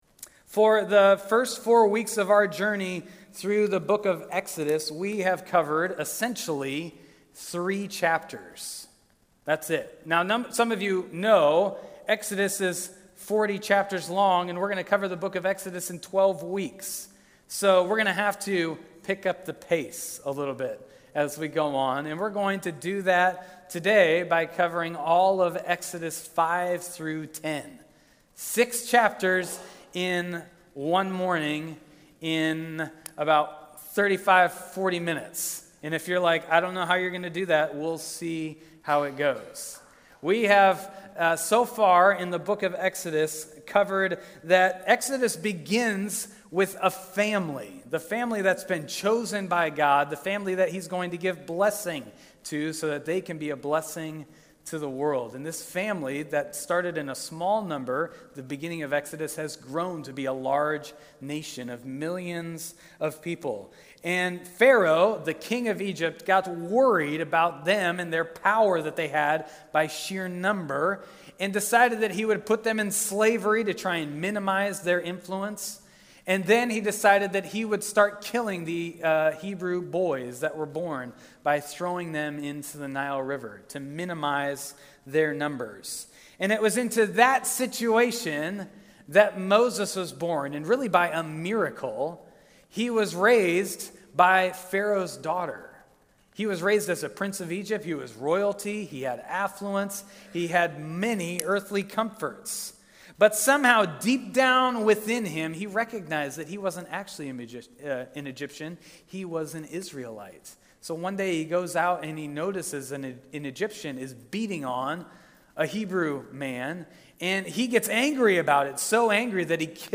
Sermon audio from March 17